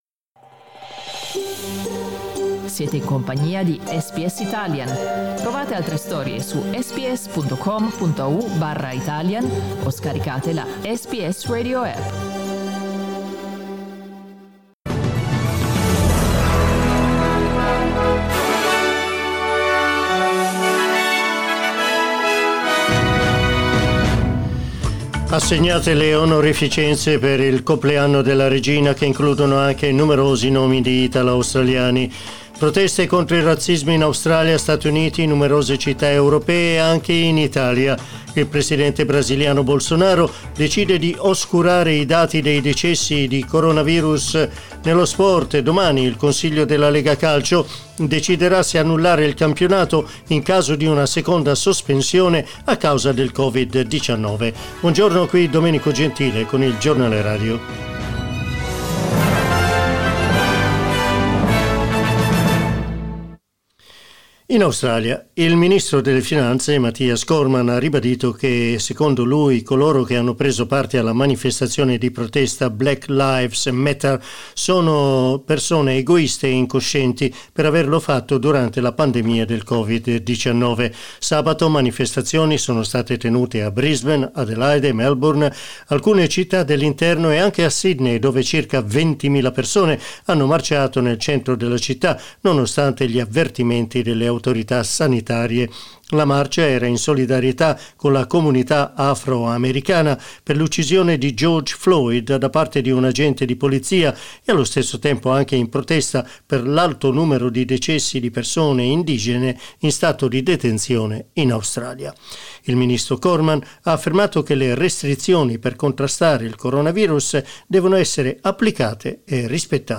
Our news bulletin in Italian